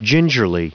Prononciation du mot gingerly en anglais (fichier audio)
Prononciation du mot : gingerly